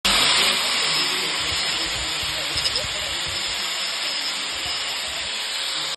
Use of grinder to trim the toe length.mp4